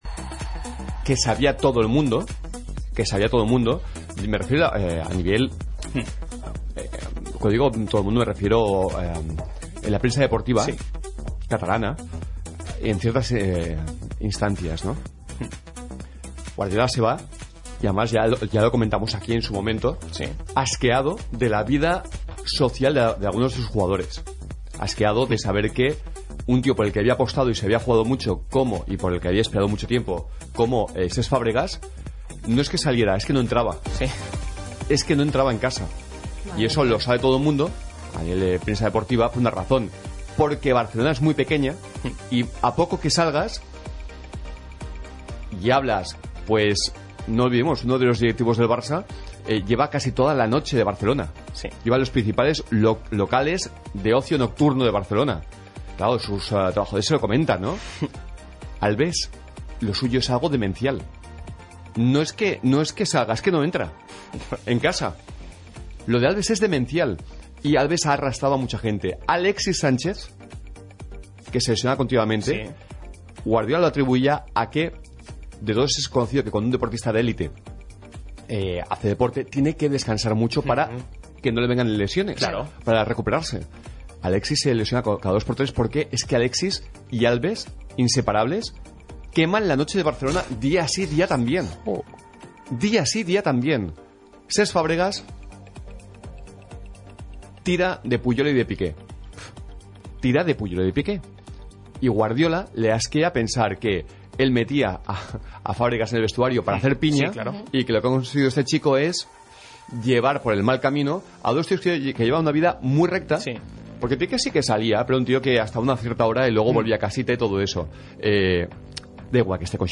Os dejo un corte de radio (no sé de qué emisora es), el cual podréis creer a pie juntillas o limpiaros el culo con él, pero sería algo muy significativo de porqué Guardiola se fue del Barça tan agotado mentalmente: